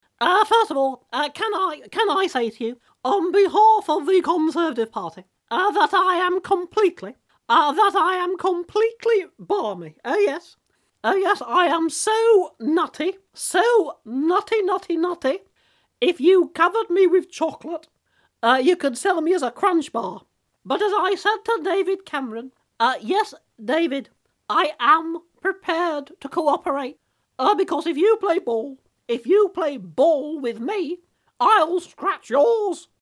Steve Nallon as Ann Widdecombe
Steve-Nallon-as-Ann-Widdecombe-First-Foremost-Entertainment-Ltd.mp3